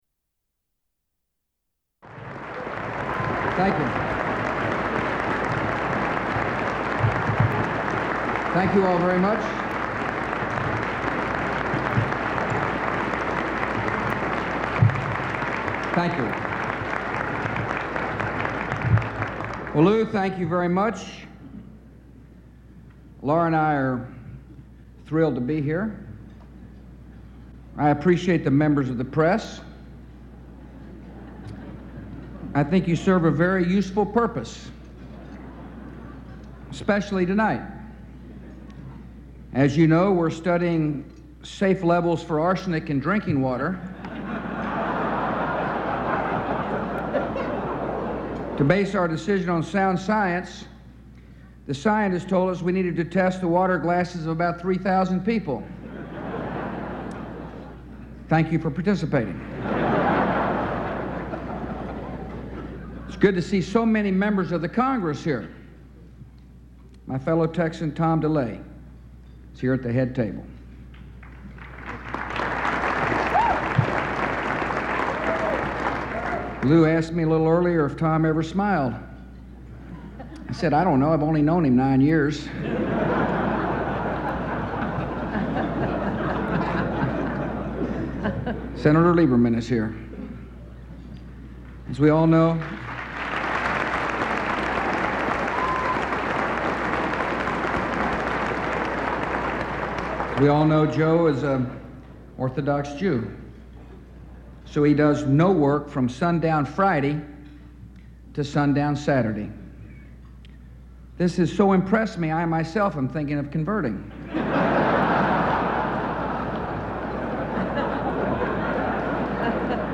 U.S. President George W. Bush speaks at the White House Radio and Television Correspondents Dinner